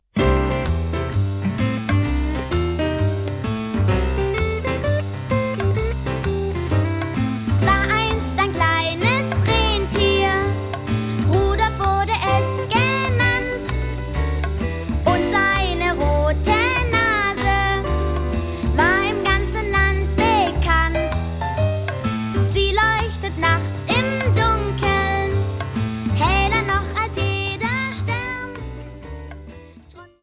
Kinder- / Jugendbuch Gedichte / Lieder